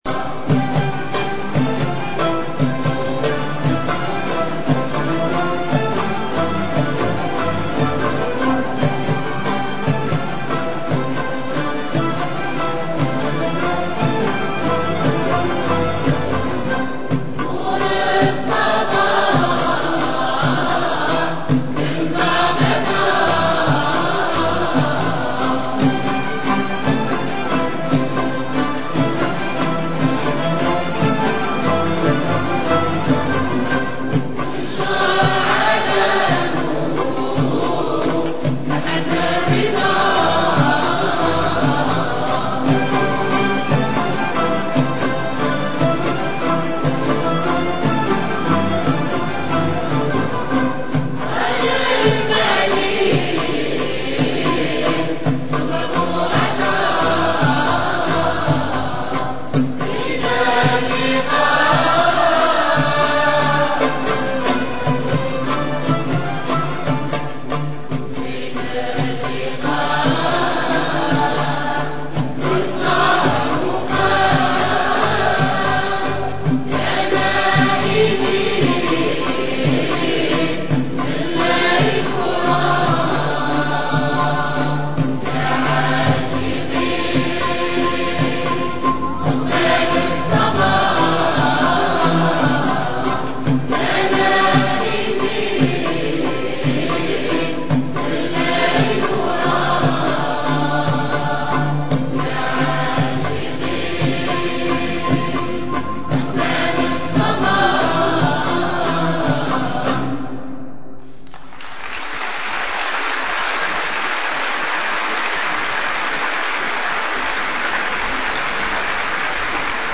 Transcrit sur la base d'un enregistrement du Grand ensemble El Mawsili de St Denis & El Djazaïria El Mossilia d'Alger ( Concert donné à la Basilique de St Denis le 05 juillet 2003).
L'introduction au Derdj est identique à la réplique instrumentale des Ghessen.
Au troisième Ghessen l'ensemble introduit une variation (changement de hauteur de la note; technique souvent utilisée pour les troisièmes vers) dans l'attaque de la première mesure.